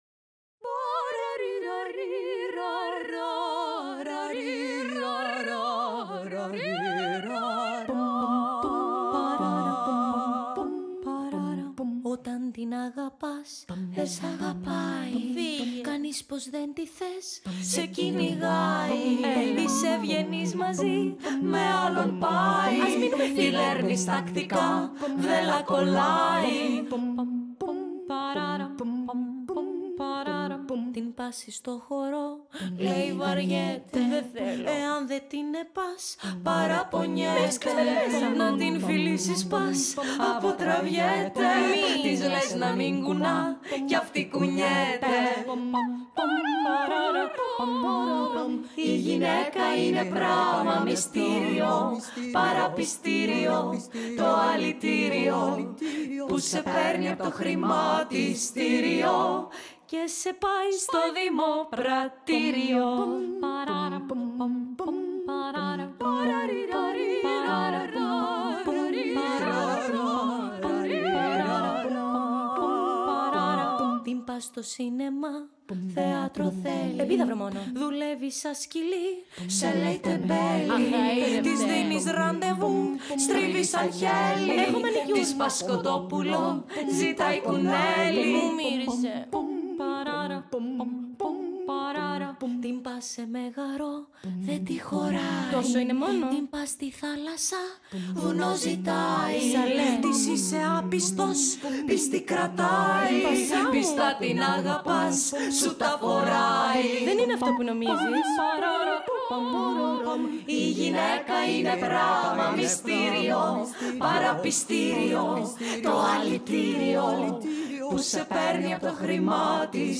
vocal group